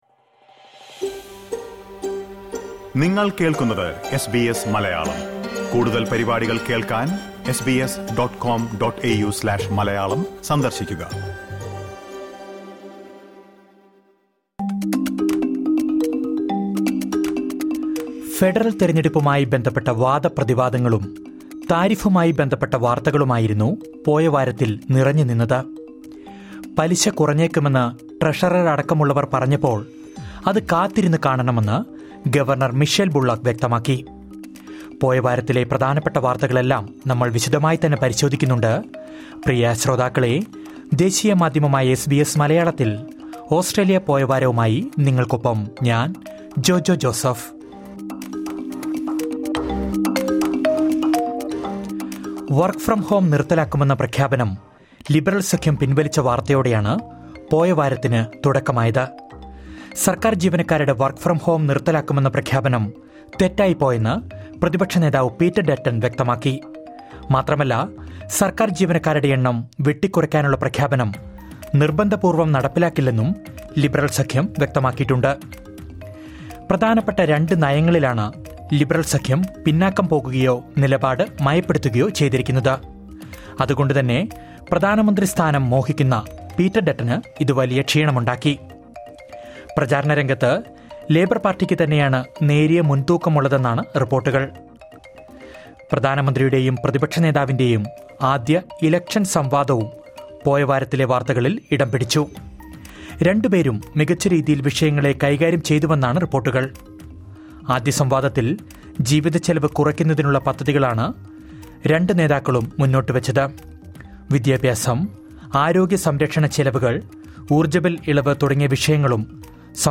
ഓസ്‌ട്രേലിയയിലെ കഴിഞ്ഞ ഒരാഴ്ചയിലെ പ്രധാന വാർത്തകൾ ചുരുക്കത്തിൽ കേൾക്കാം...